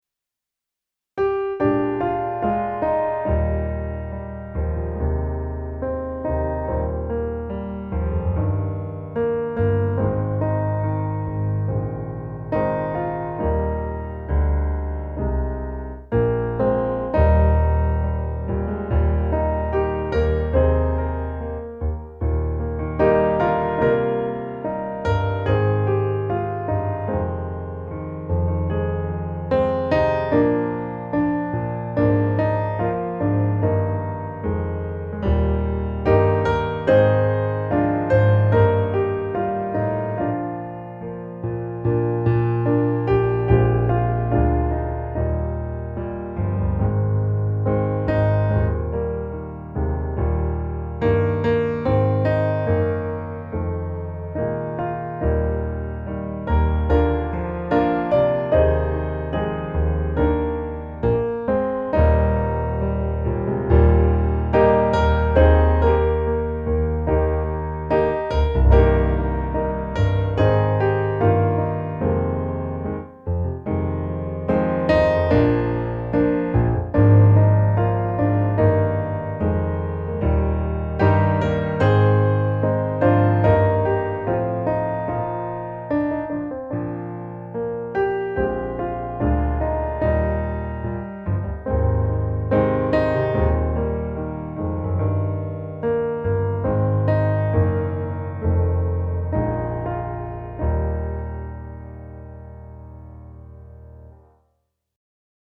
Må din väg gå dig till mötes - musikbakgrund
Musikbakgrund Psalm